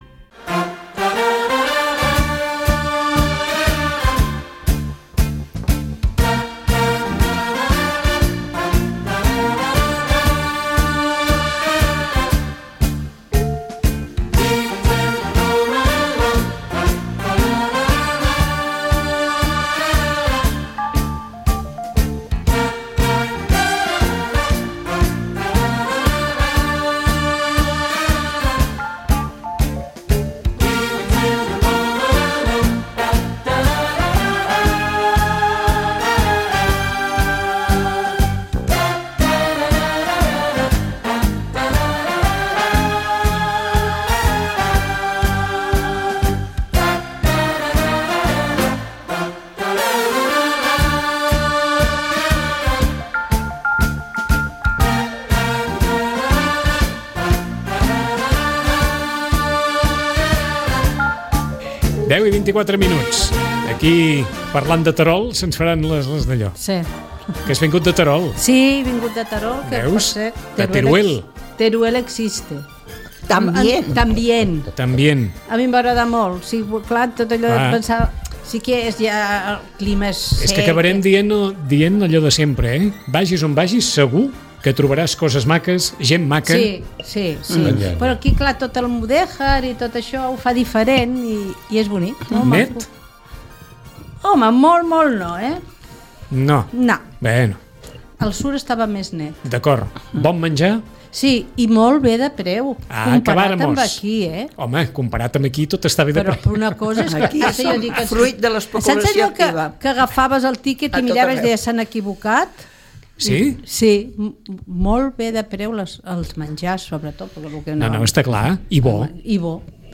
Tertúlia